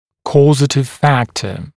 [‘kɔːzətɪv ‘fæktə][‘ко:зэтив ‘фэктэ]причинный фактор